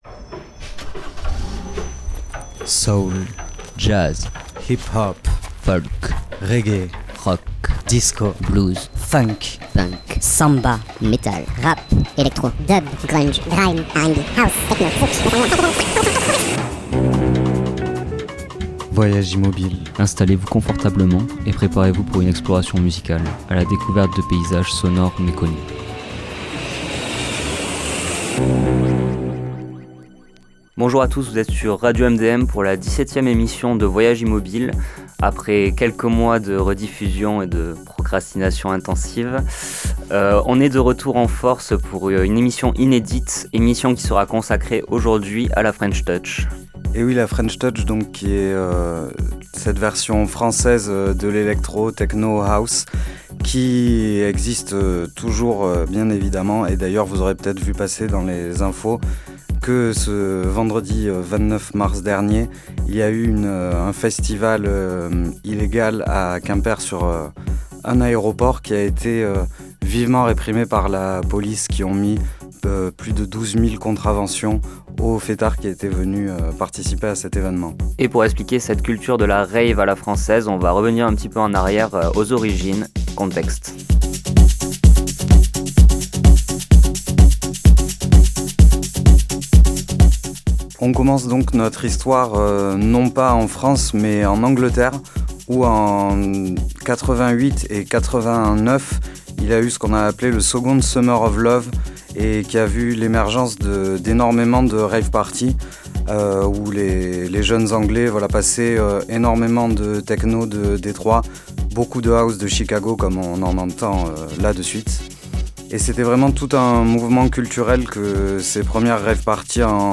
Venez assister à l’émergence de la French Touch, mélange savant de techno, House et un je ne sais quoi à la française.